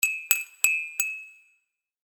ガラス風鈴3.mp3